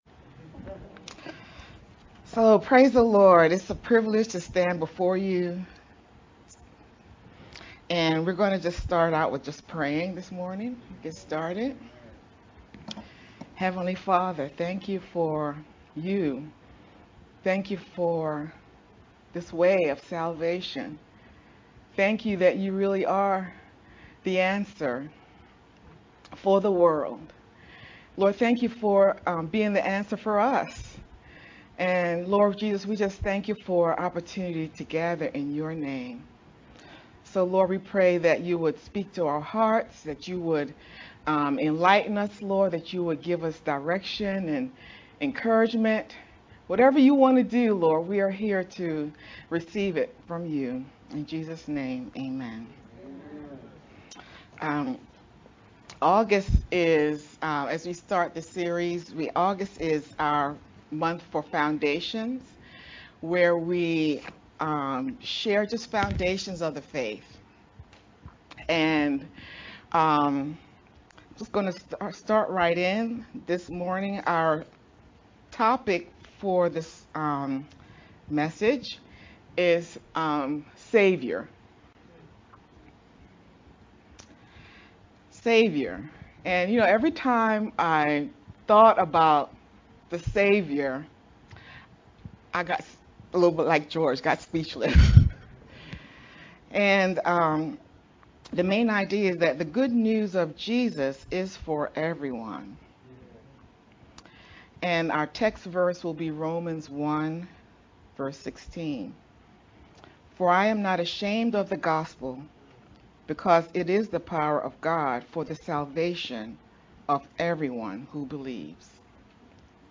Aug-7th-Sermon-only-Mp3-CD.mp3